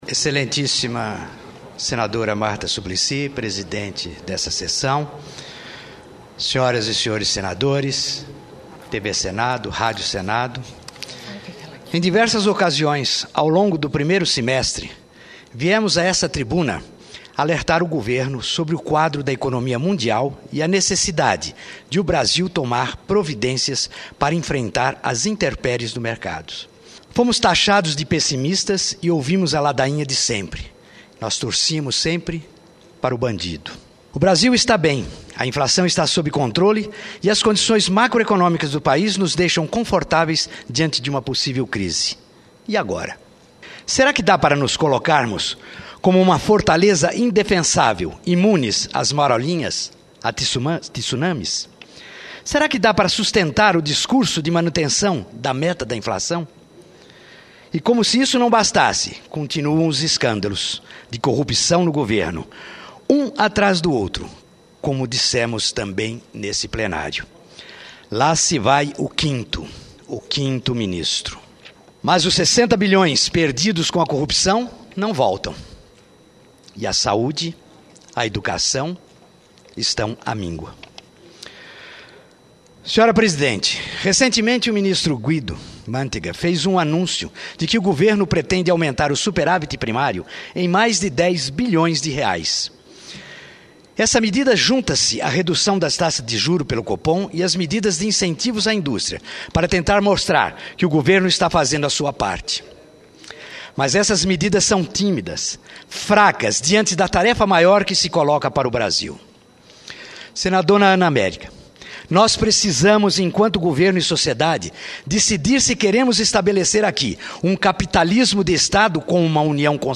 O senador Cyro Miranda (PSDB-GO) disse que em várias ocasiões alertou o governo federal sobre o quadro da economia mundial e a necessidade de o Brasil tomar providências para enfrentar as intempéries do mercado. O senador questionou se neste momento o governo ainda vai sustentar o discurso de manutenção da meta da inflação, além de enfrentar o problema da corrupção. Miranda criticou as medidas do governo e pediu que a política econômica brasileira seja revisada para desonerar as famílias e fortalecer o mercado interno com menos impostos.